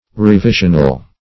revisional - definition of revisional - synonyms, pronunciation, spelling from Free Dictionary
Revisional \Re*vi"sion*al\, Revisionary \Re*vi"sion*a*ry\, a.